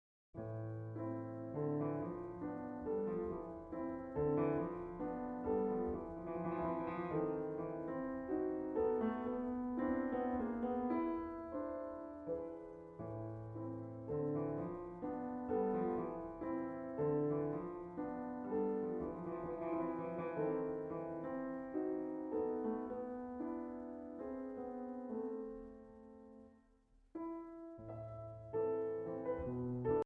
Piano/Organ Solo Piano Music Composer Collections and Singles
Idil Biret
Piano